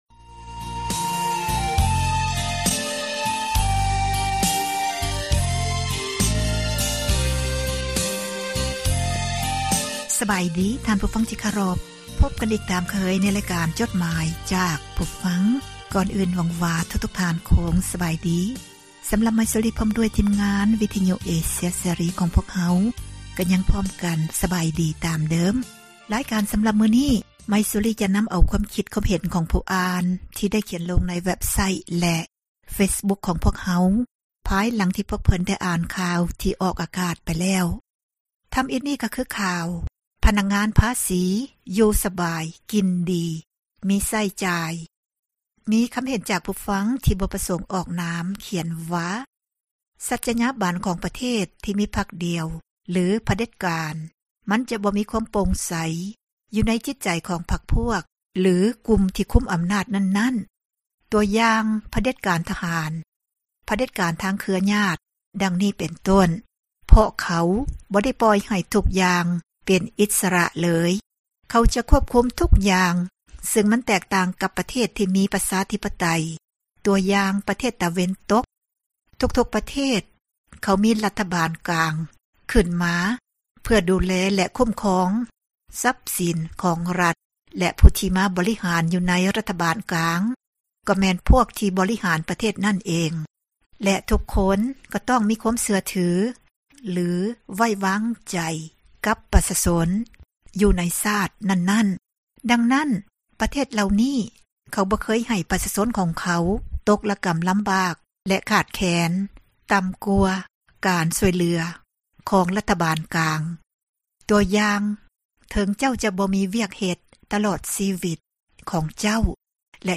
ອ່ານຈົດໝາຍ